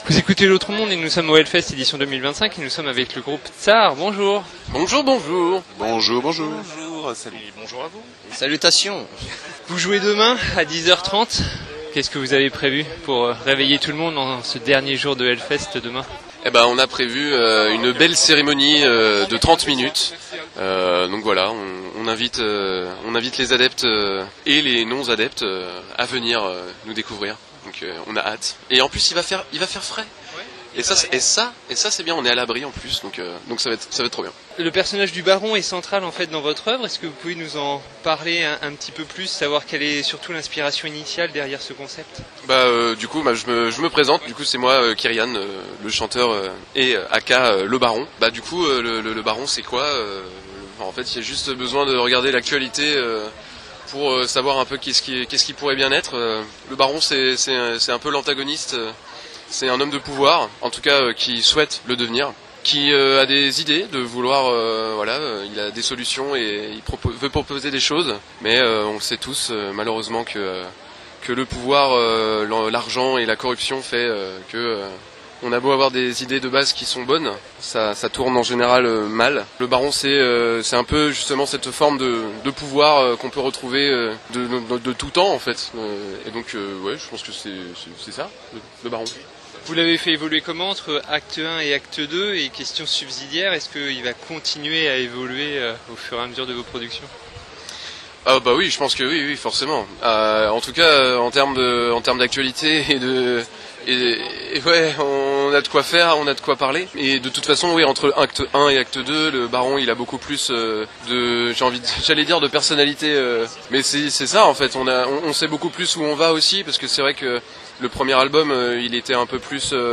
itw.Tsar.Hellfest.2025.mp3